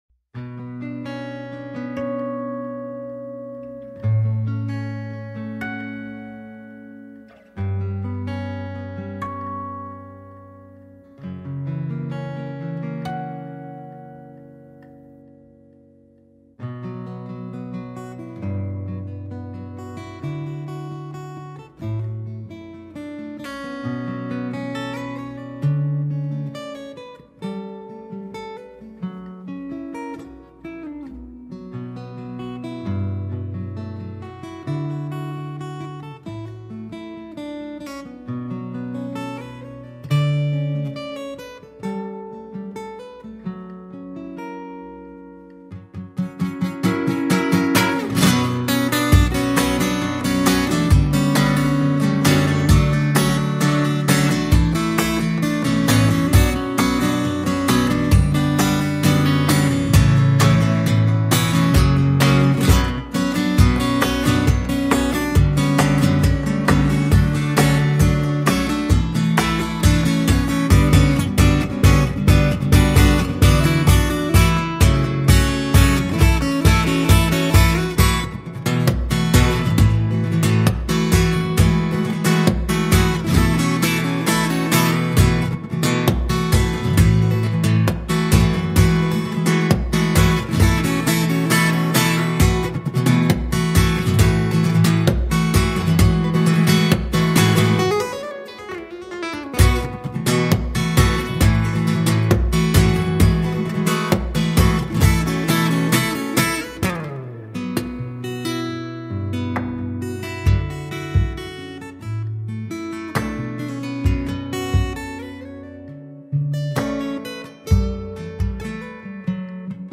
Fingerstyle Guitar Cover